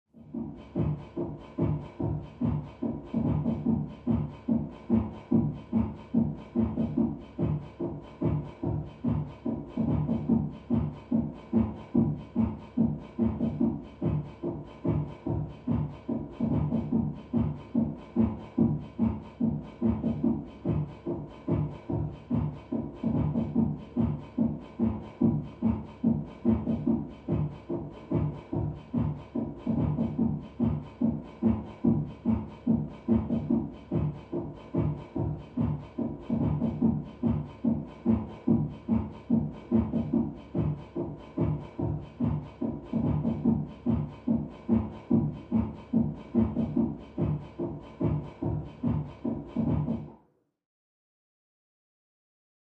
Music; Electronic Dance Beat, From Next Room.